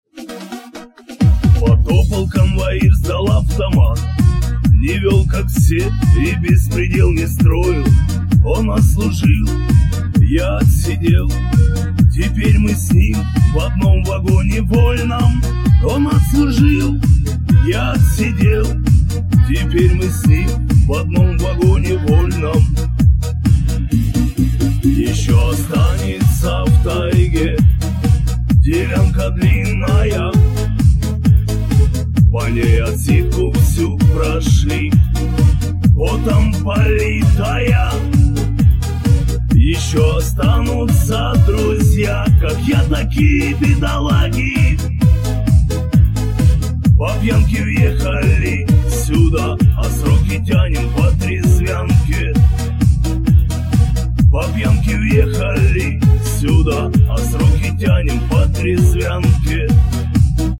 мужской голос
спокойные
блатные
тюремные
цикличные